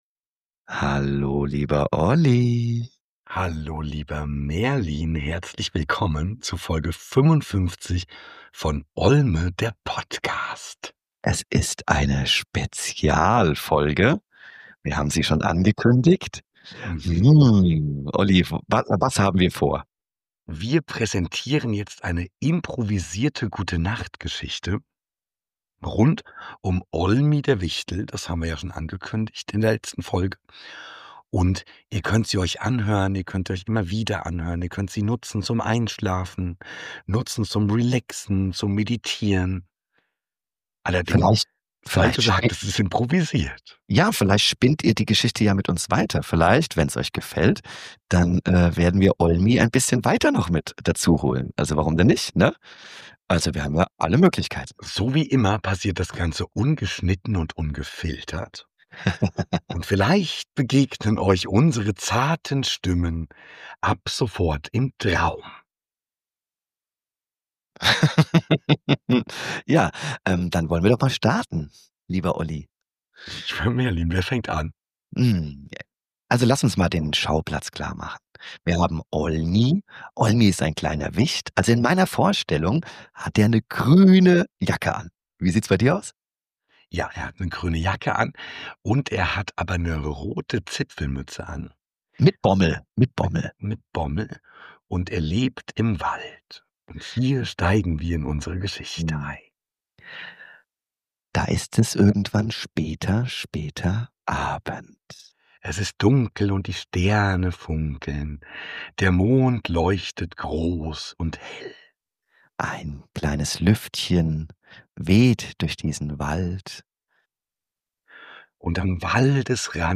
Verspielt, träumerisch und herrlich verrückt – perfekt zum Einschlafen, Runterkommen oder für ein wohliges Lächeln vorm Einschlafen.